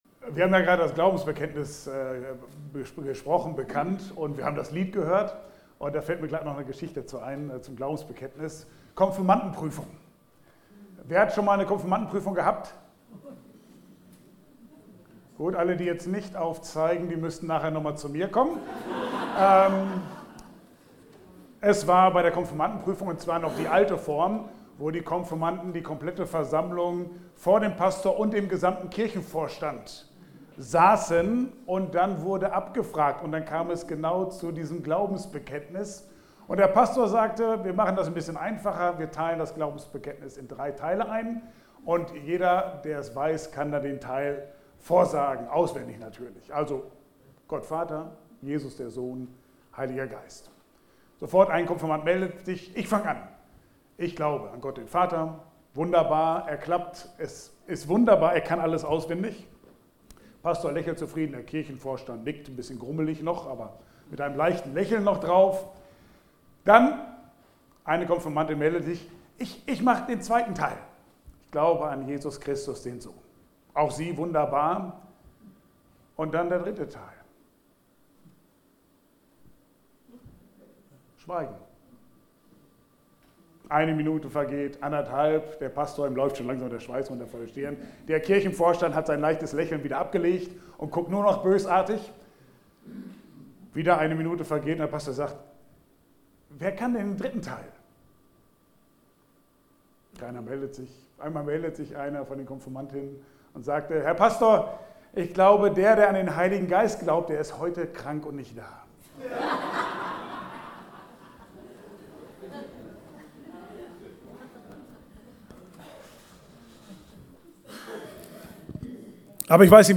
Bibelstelle: Johannes 16, 5-15 Dienstart: Gottesdienst